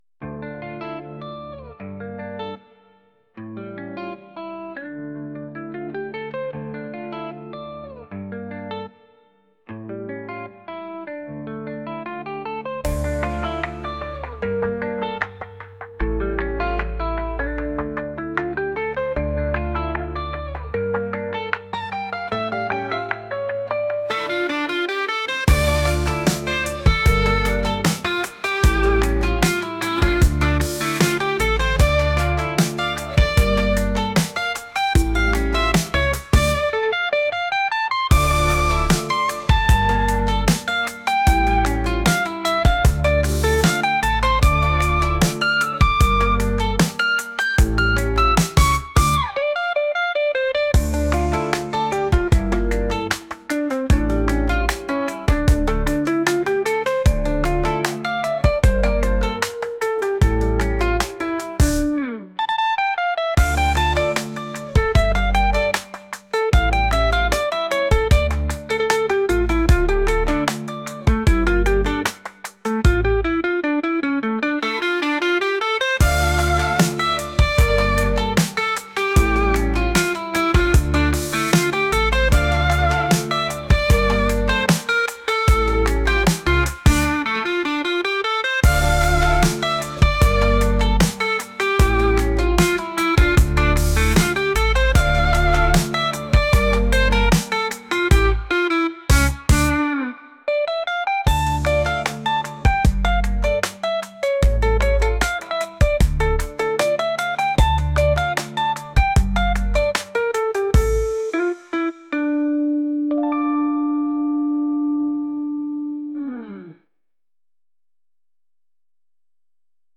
Инструментала